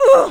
hurt1.wav